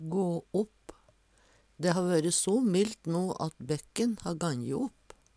DIALEKTORD PÅ NORMERT NORSK gå opp bruka om is som smeltar og vatnet kjem til synes Eksempel på bruk Dæ ha vøre so millt no at bekken ha ganje opp.